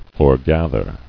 [fore·gath·er]